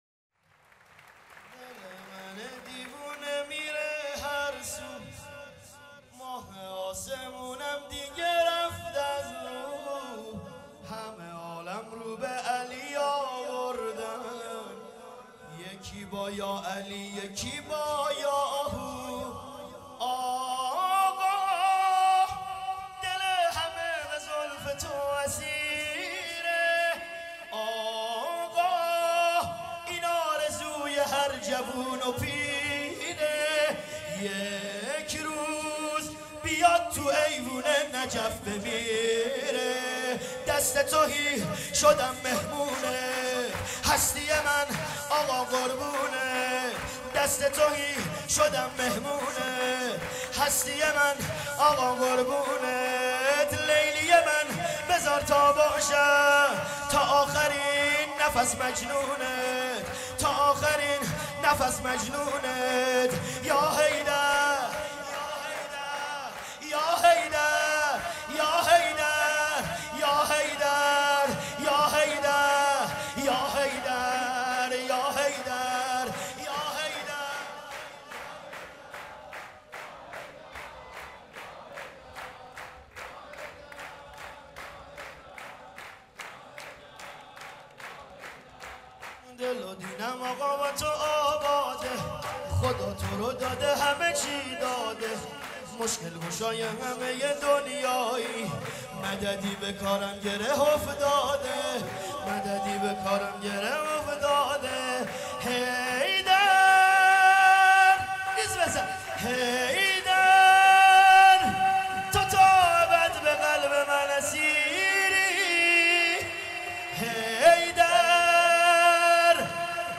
چهاراه شیرودی حسینیه حضرت زینب (سلام الله علیها)
مدح امیرالمومنین(ع)